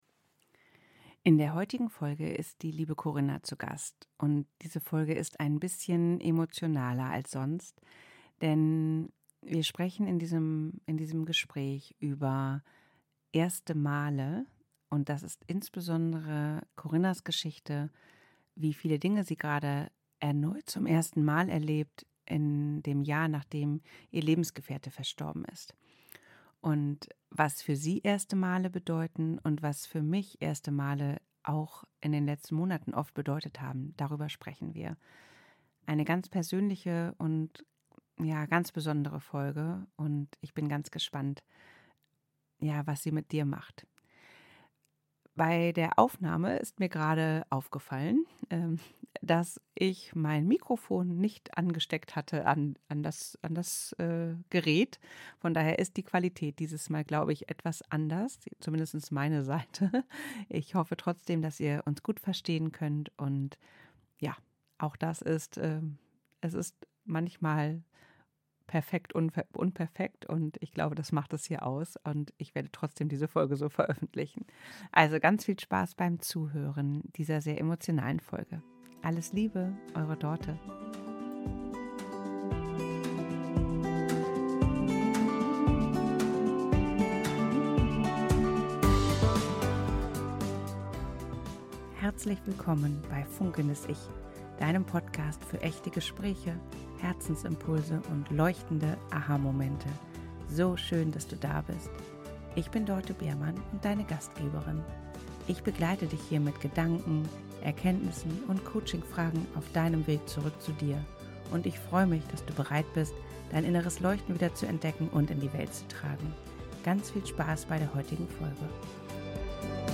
ein Sofa-Gespräch